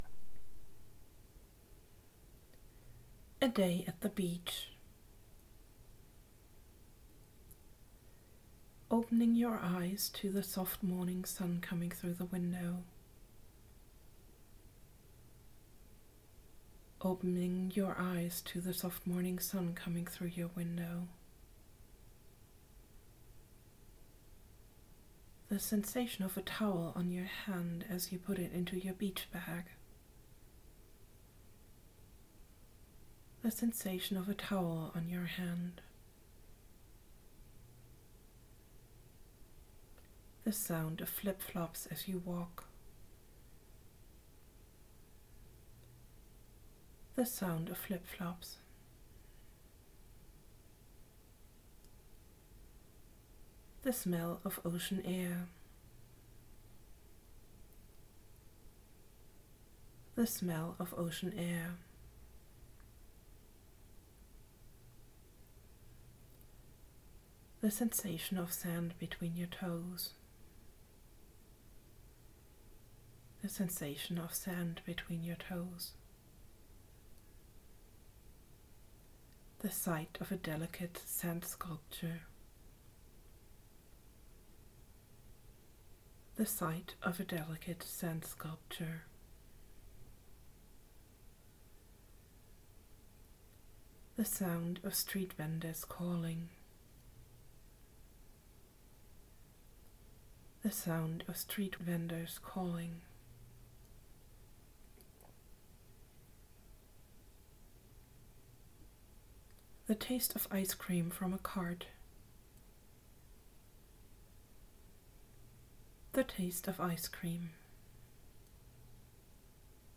by on in english, guided imagery
In this short-form approach you get instructions to focus on imagining one sensory experience after the other. The instruction is repeated twice and then you are asked to shift to the next picture or sensory experience immediately. It is supposed to be so fast-paced that you have no time to come up with negative ideas or elaborate inner stories.